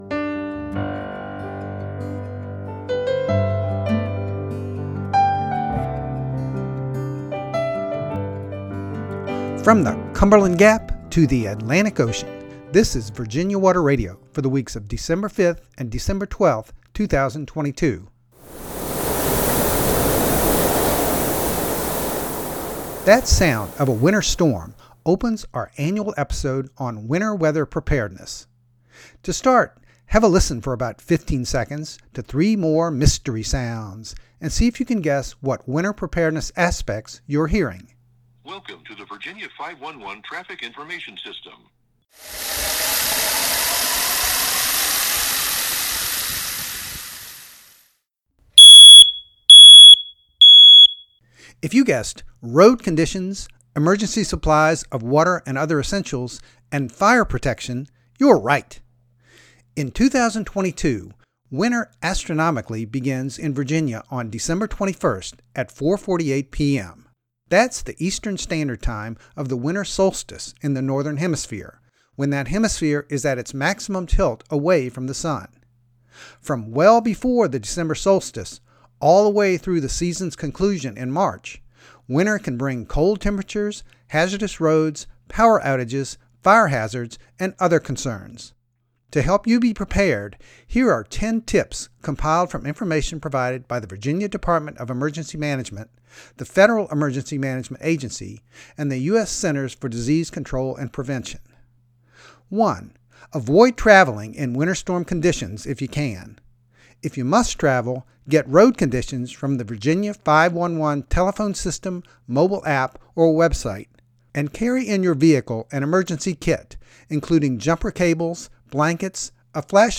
The running water sounds and smoke alarm were also recorded by Virginia Water Radio.